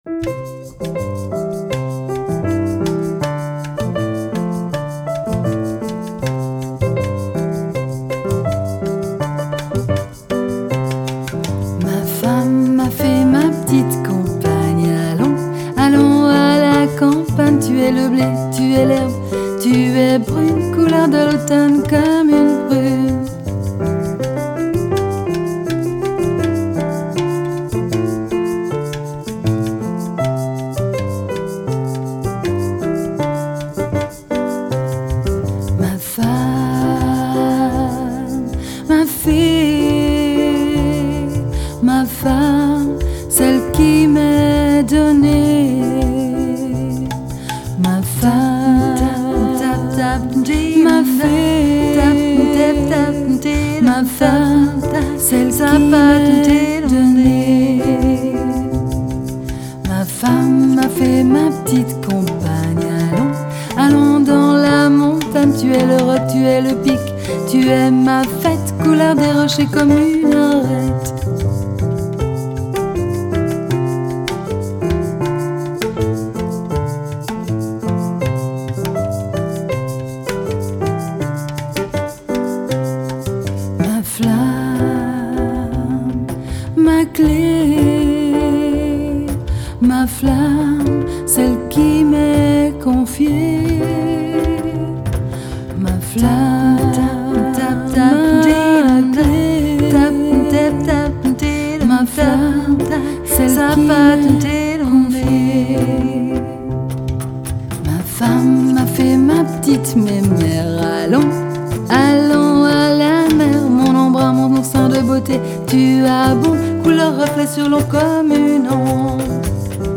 piano
diverses percussions